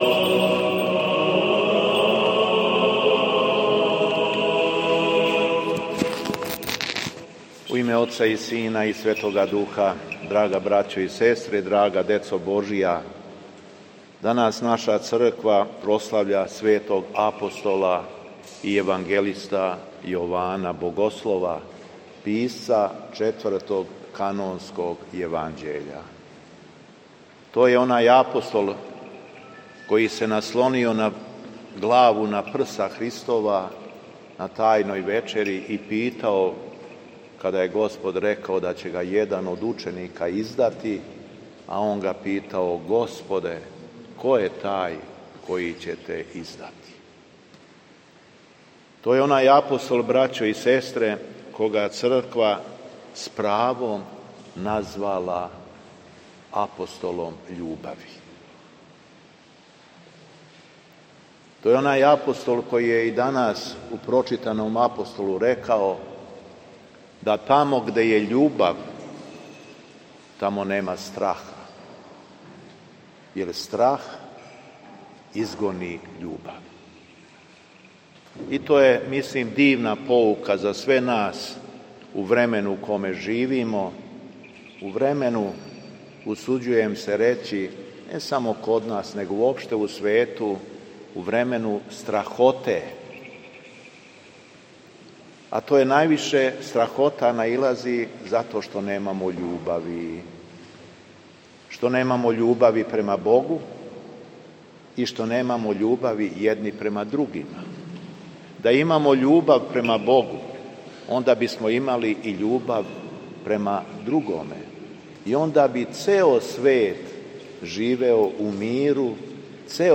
Беседа Његовог Високопреосвештенства Митрополита шумадијског г. Јована
После прочитаног Јеванђелског зачала беседио је владика Јован.